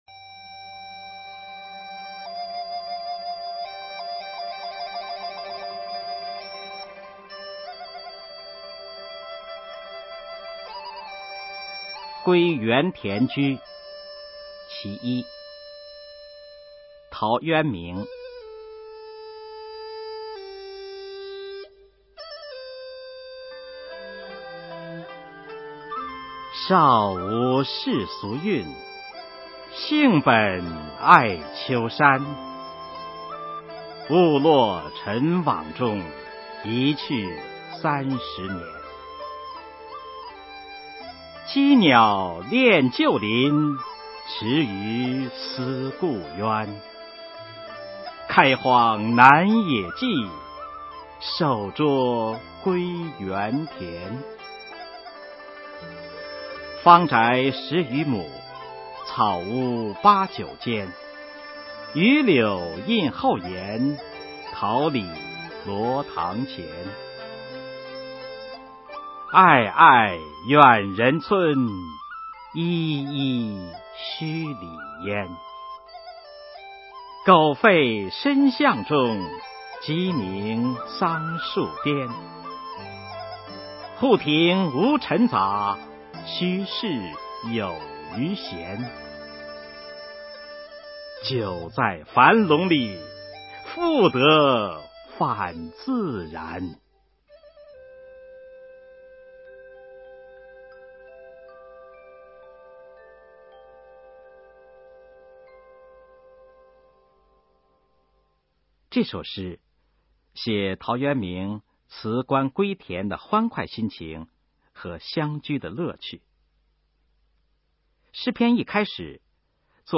《归园田居》（其一）原文和译文（含朗读）　/ 陶渊明
语文教材文言诗文翻译与朗诵 高中语文必修一 目录